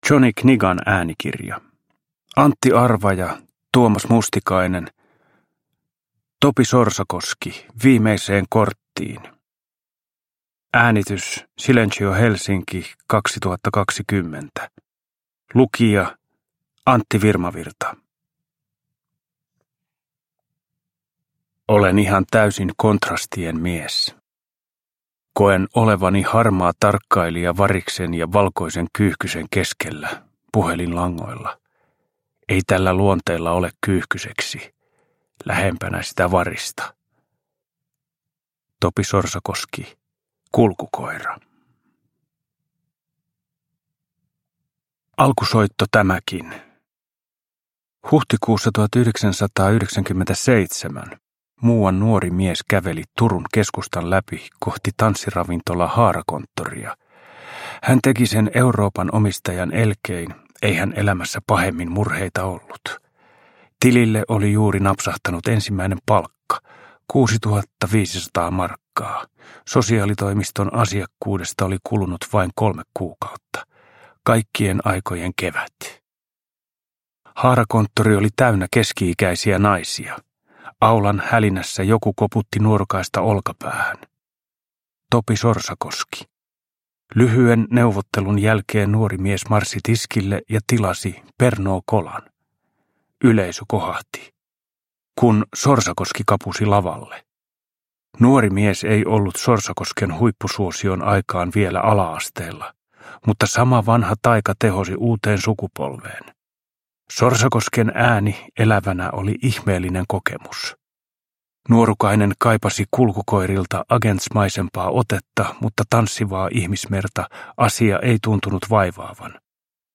Topi Sorsakoski – Ljudbok